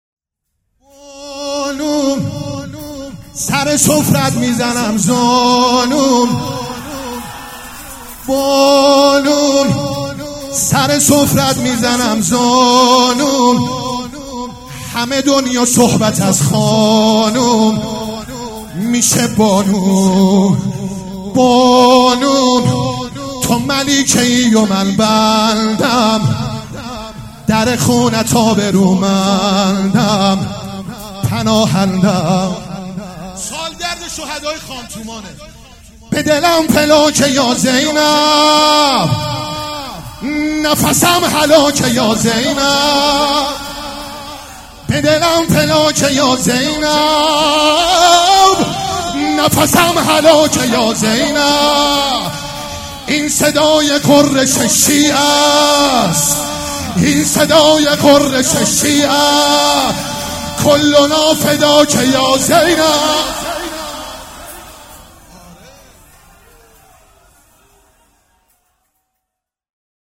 شعر خوانی
مداح
ولادت حضرت زینب (س)